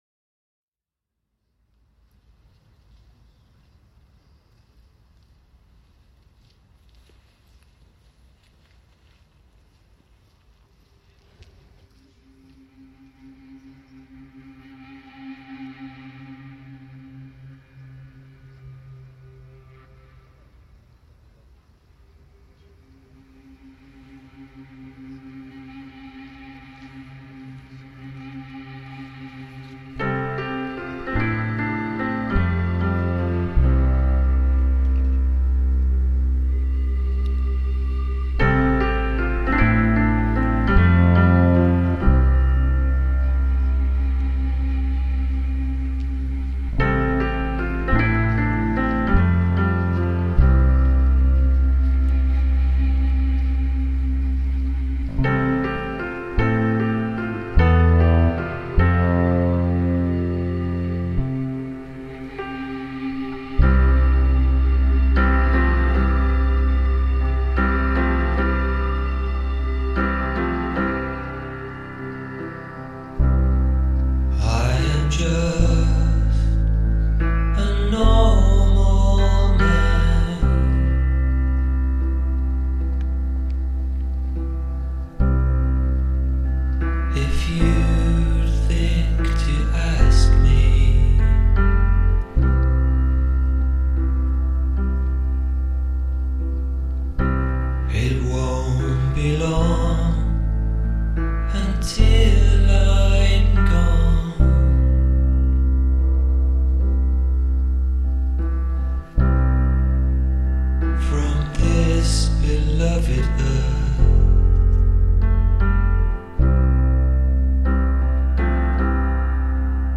Arbeit Macht Frei gate at Auschwitz reimagined